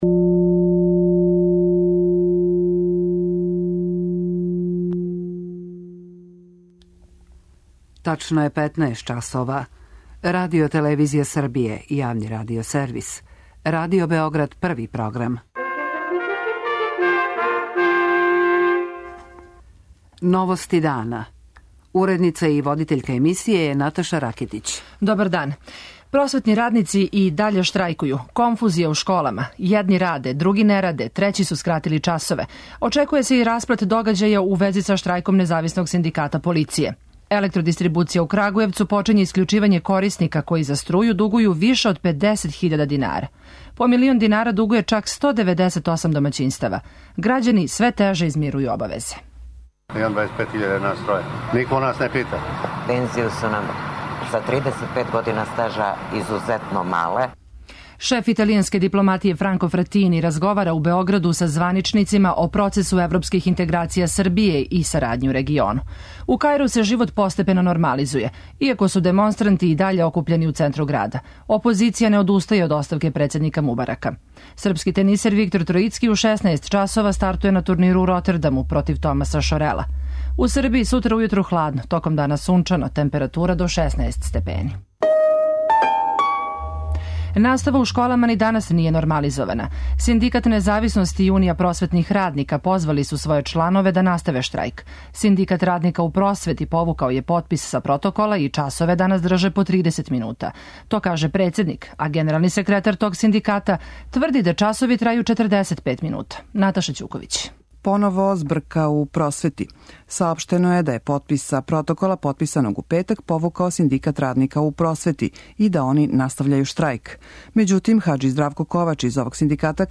У Новостима ћемо чути и репортажу из Крагујевца, где "Електрошумадија" почиње с обавештавањем нередовних платиша да ће им наредних дана бити искључена струја.